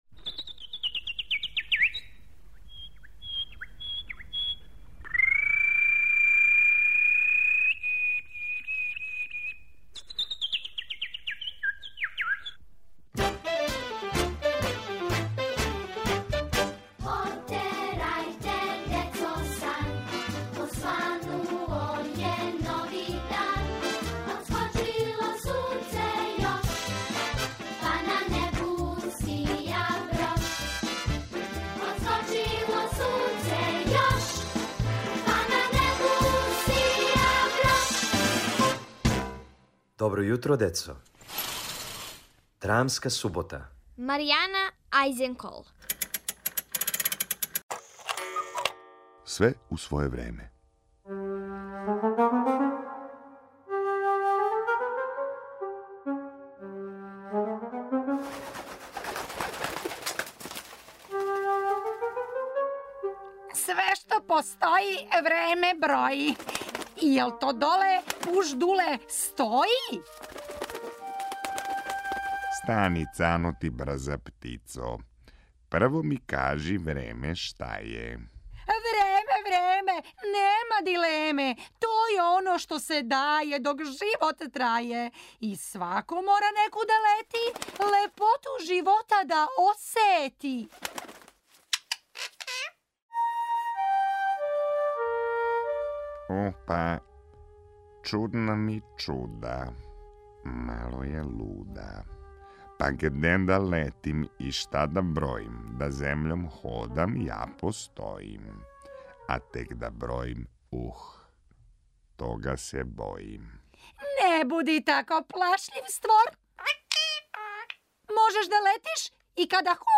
Слушате први део мале радио драме "Све у своје време". У њој, казаљке су одлучиле да напусте сат.